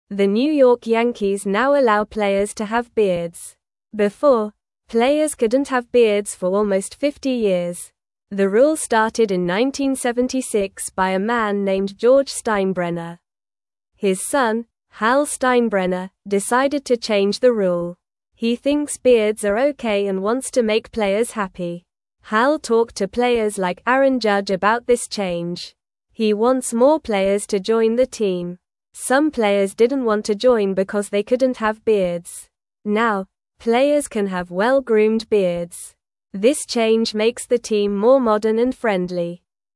Normal
English-Newsroom-Beginner-NORMAL-Reading-Yankees-Players-Can-Now-Have-Beards-Again.mp3